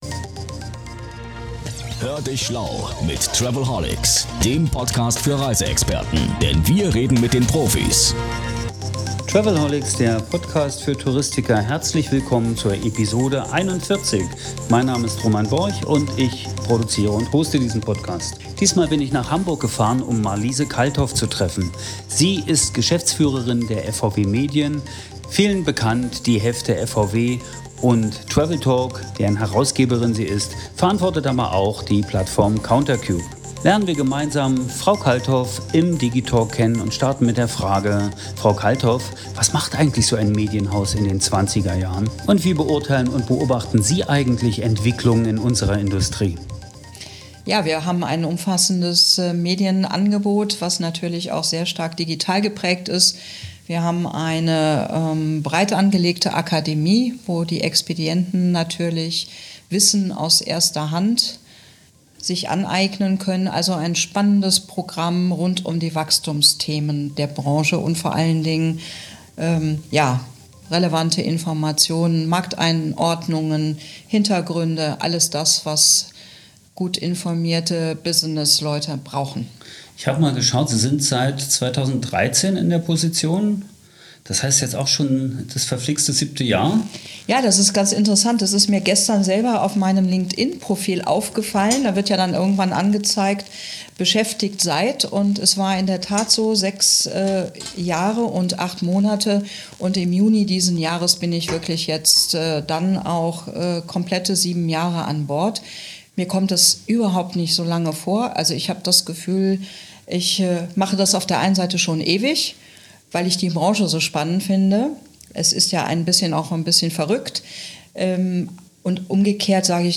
Neugierde trifft Analyse und es wird ein interessantes und spannendes Gespräch.
Konzentriert und charmant kurzweilig, nur ab und an gestört durch ein ein Mobiltelefon, das leise knistert.
Nichts wird geschnitten